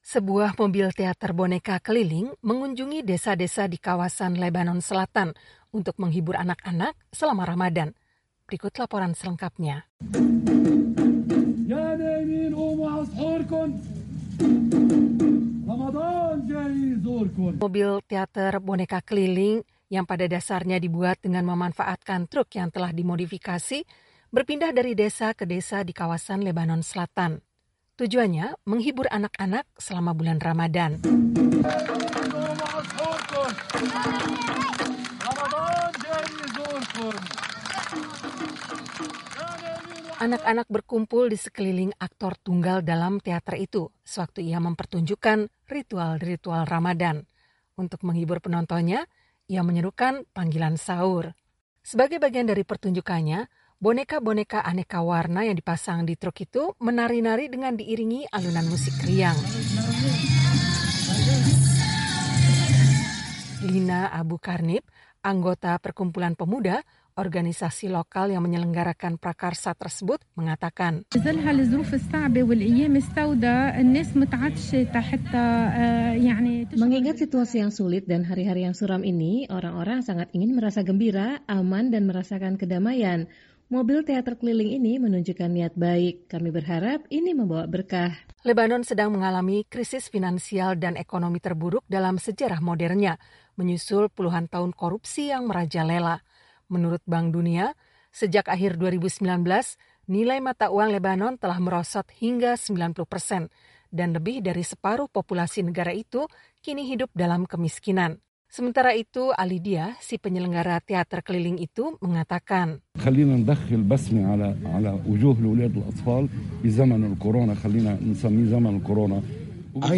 Untuk menghibur penontonnya, ia menyerukan panggilan sahur.
Sebagai bagian dari pertunjukannya, boneka-boneka aneka warna yang dipasang di truk itu menari-nari dengan diiringi alunan musik riang.